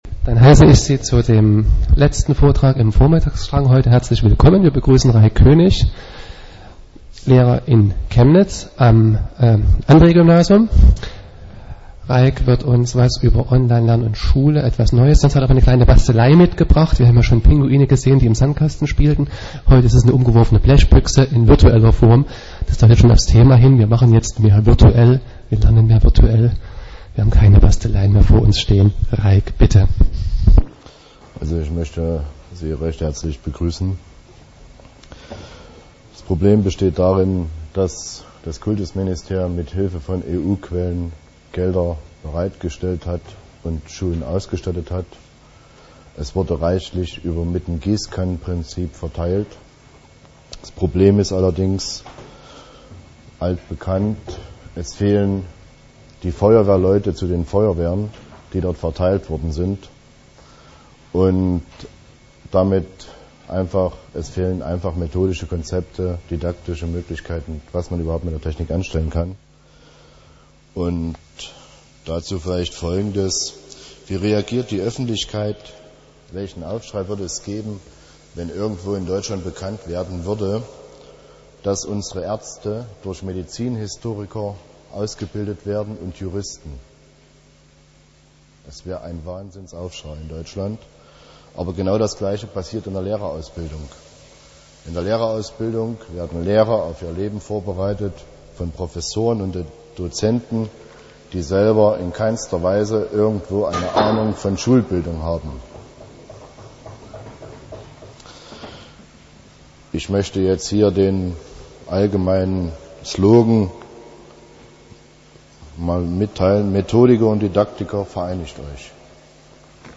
Der 5. Chemnitzer Linux-Tag ist eine der größten Veranstaltungen seinder Art in Deutschland.